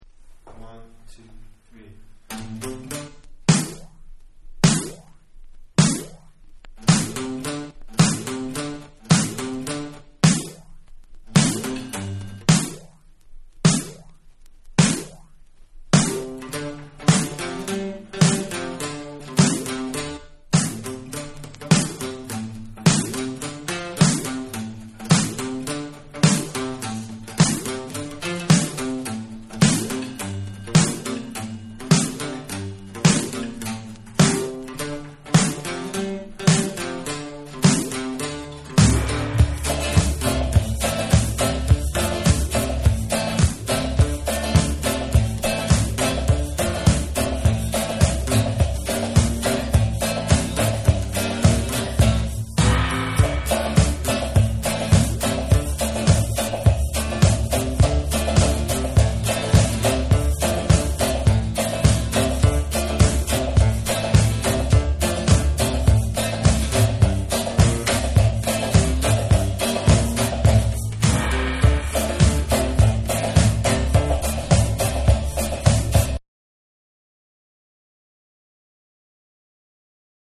AFRO CUBAN JAZZ
BATA DRUM